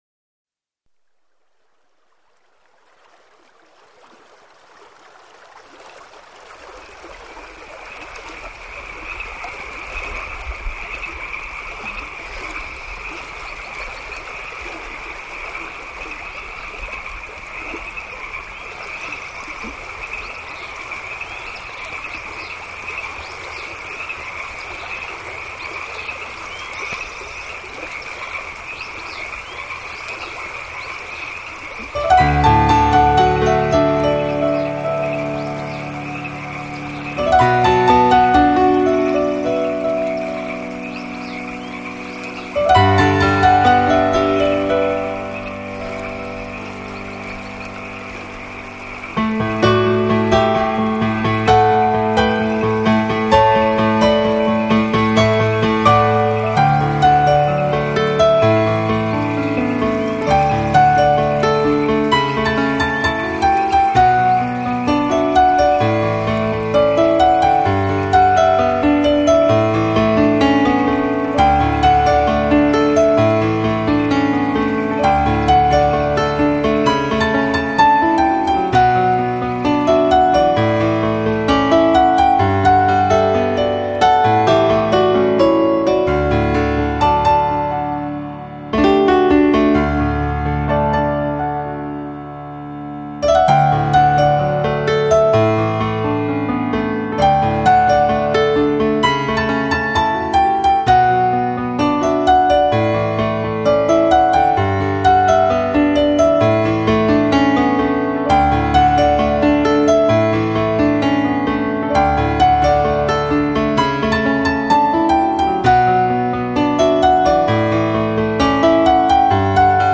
艺术家: 纯音乐